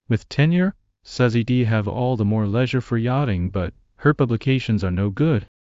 multilingual text-to-speech voice-cloning